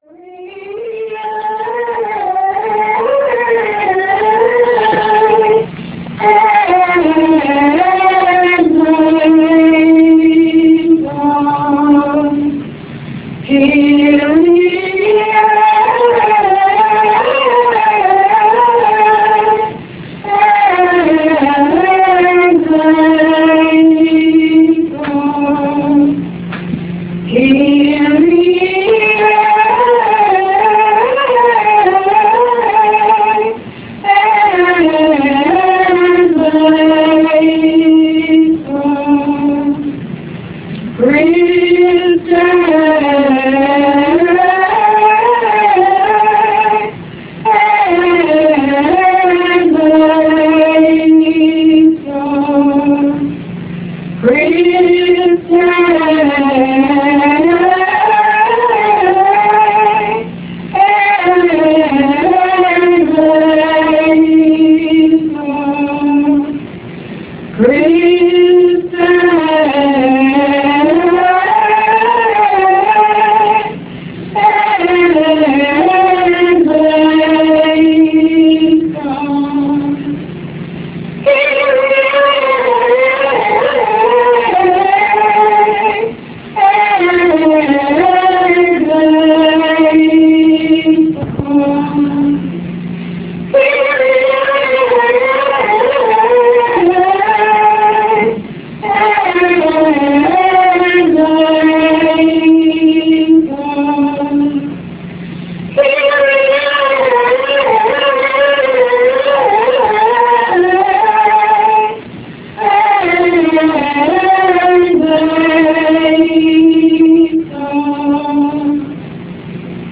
The Gregorian Chant 15.3 Minutes High Quality Wave Format (.WAV Format) - 7.2 M Low Quality Real Media (.RM) - 773 KB Download Now to play Real Media « HOME » © 1965 Catholic Traditionalist Movement Inc.